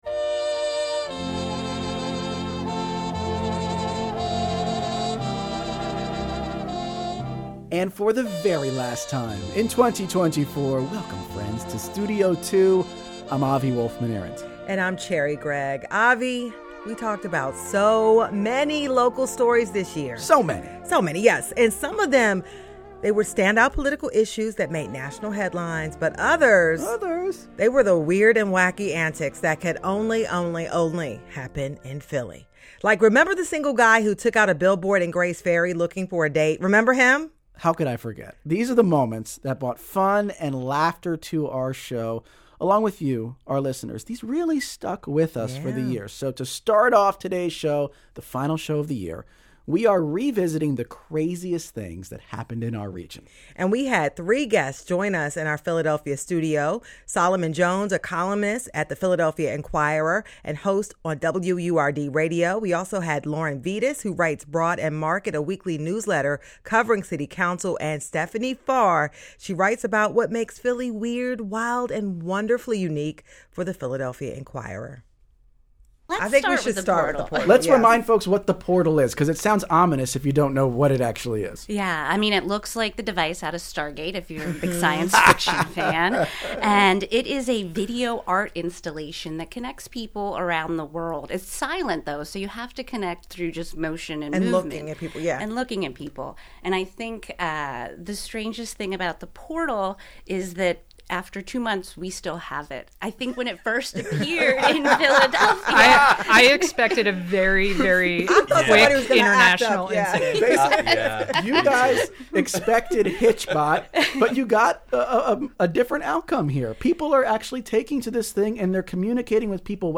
Guest: Chang-rae Lee